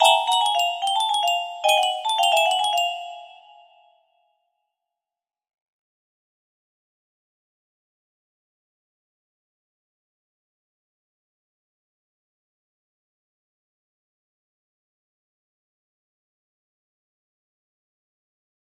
Unknown Artist - Untitled music box melody
Yay! It looks like this melody can be played offline on a 30 note paper strip music box!